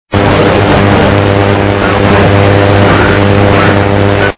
И эта же помеха в режиме АМ на фоне почти местного киловаттника(до +40 без помехи слышен):
М,даа, прям сварочник под трансивером.